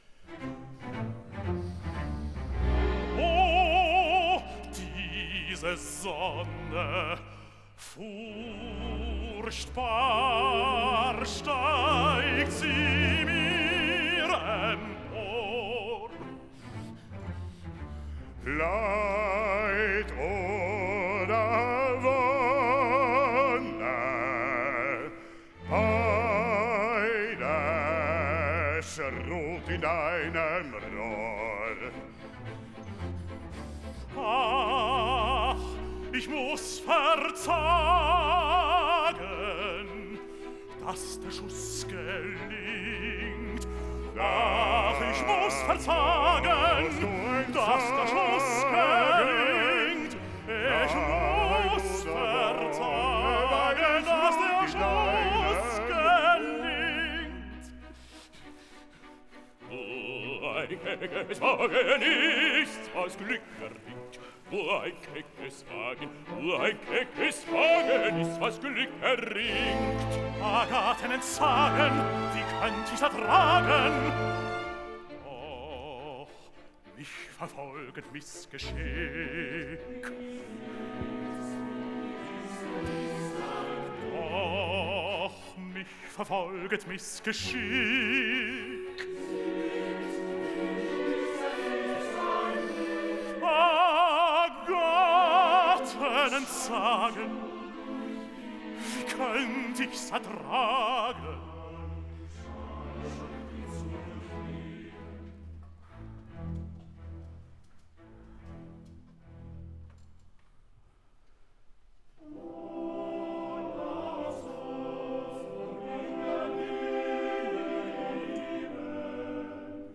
Terzett Bis T108
terzett-bis-t108.mp3